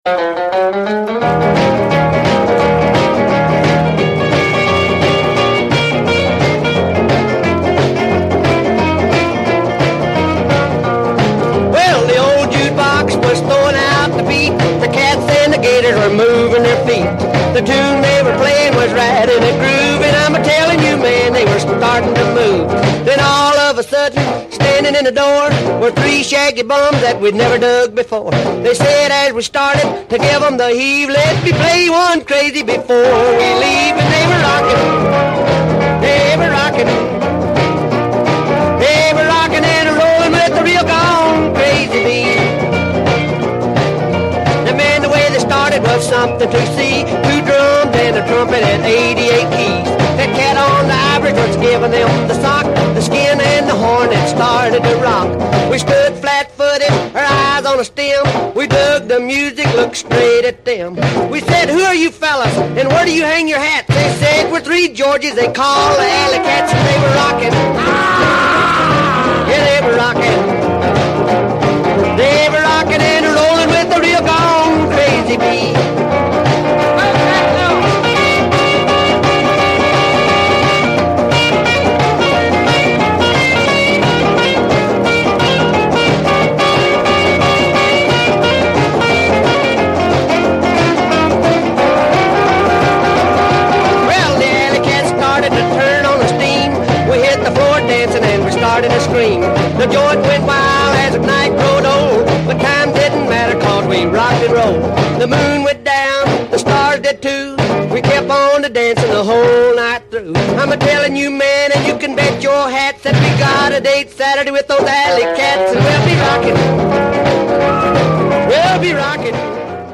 Country & Western/Rockabilly